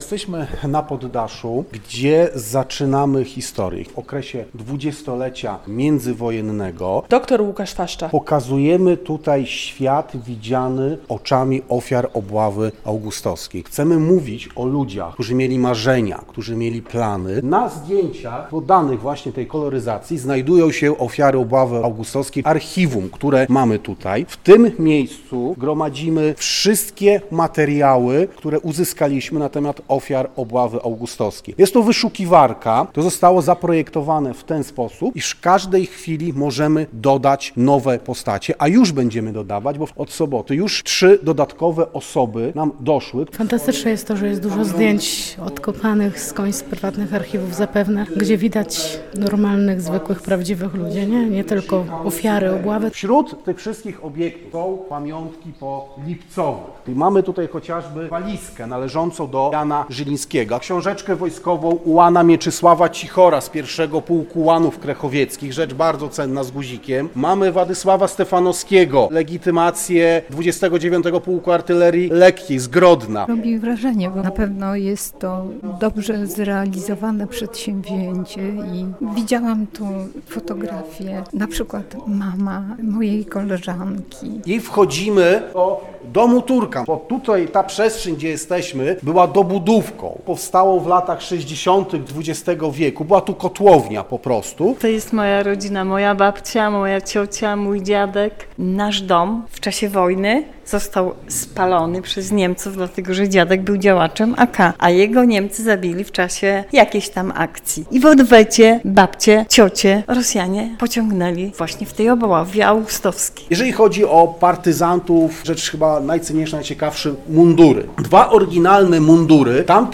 Zwiedzanie Domu Pamięci Obławy Augustowskiej - relacja